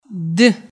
b (bottle) at beg. of word